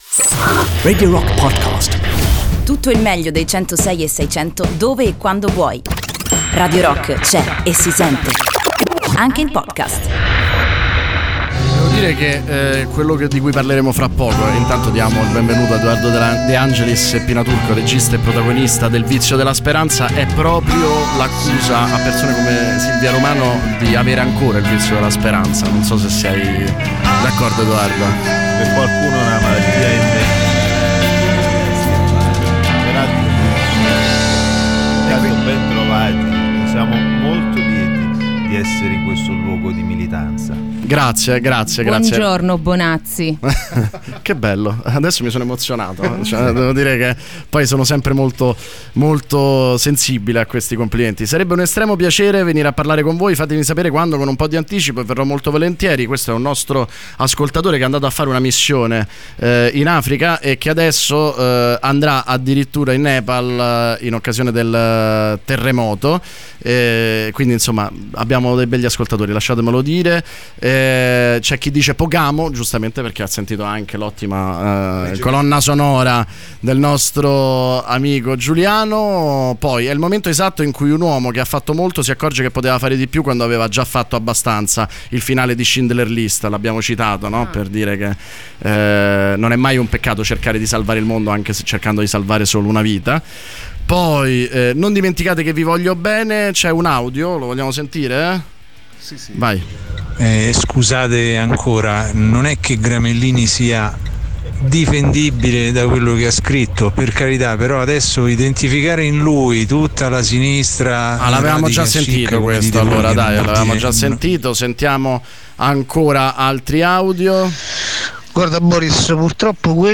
Intervista: Pina Turco e Edoardo De Angelis (22-11-18)